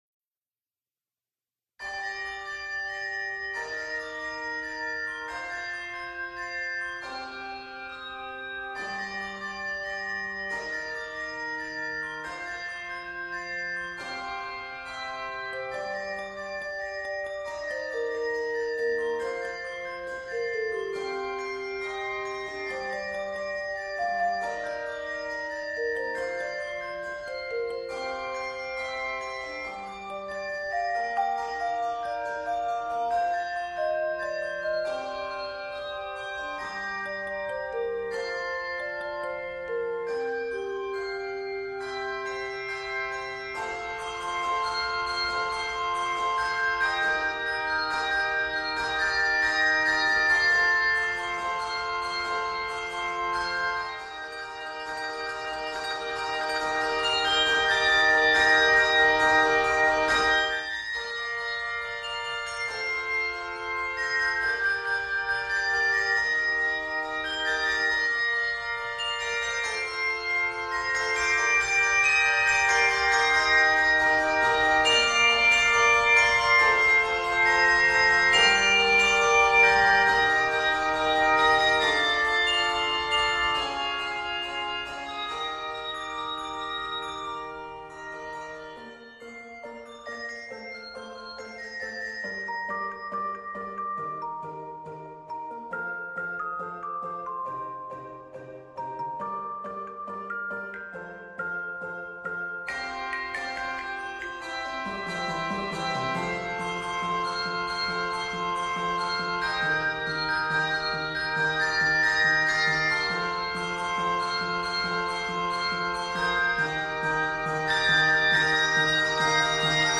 uplifting, empowering, and pure magic!
Key of Bb Major.